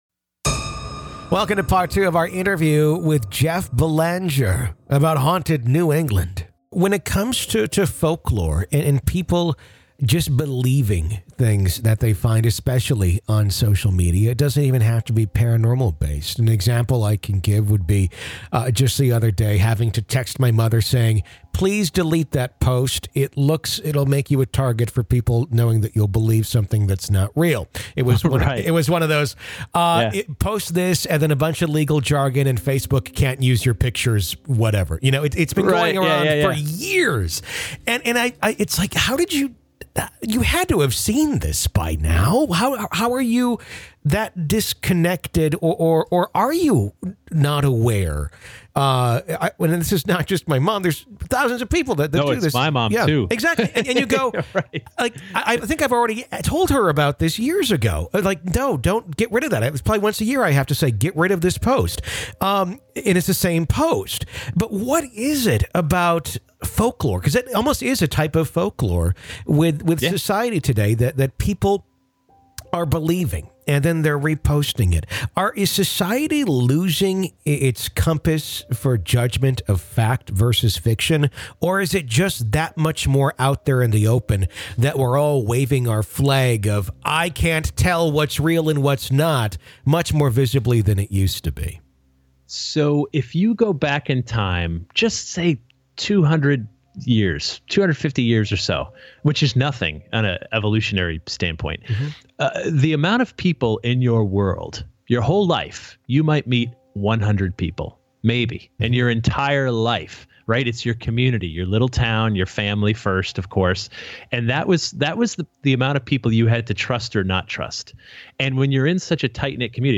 Along the way, hear field recordings of phantom footsteps, learn the best (or worst) nights to visit haunted bridges, and discover why folklore may hold more truth than we dare admit. This is Part Two of our conversation.